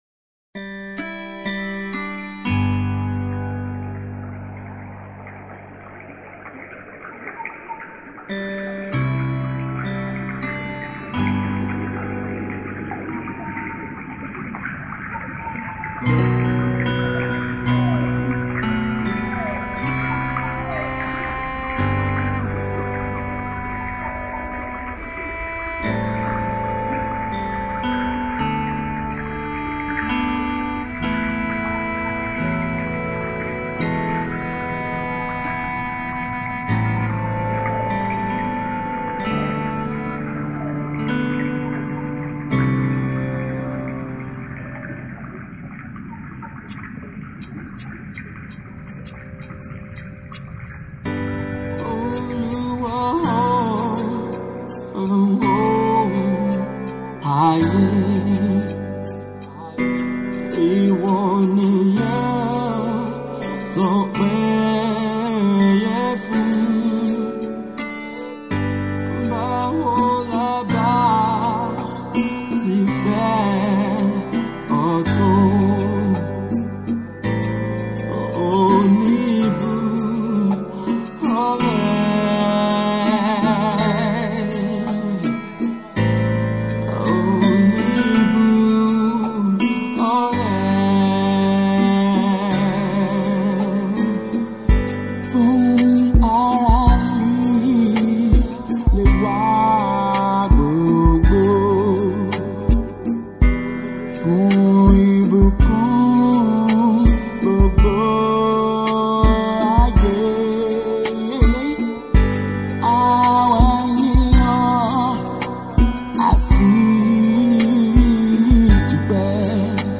Prolific gospel singer
hymn